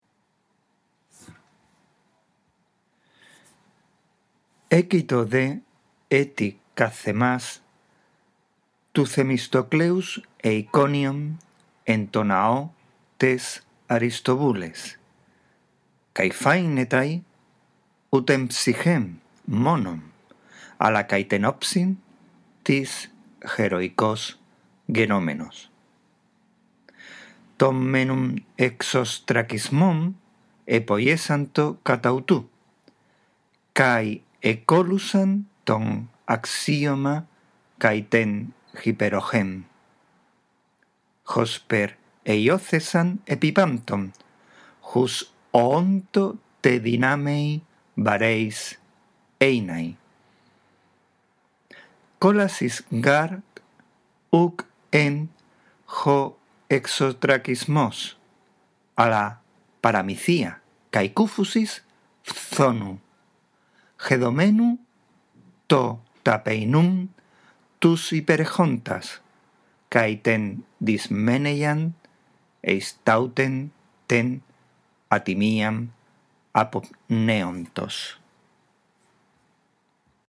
Esta audición del texto te guiará en la práctica de la lectura